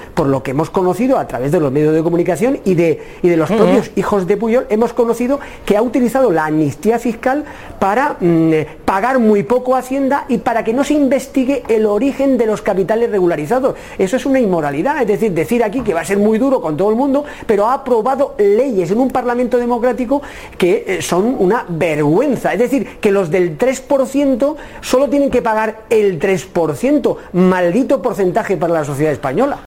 Declaraciones de Pedro Saura en Al Rojo Vivo.